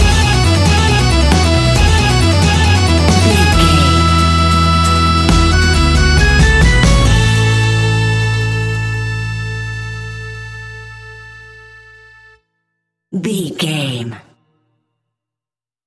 Ionian/Major
acoustic guitar
mandolin
double bass
accordion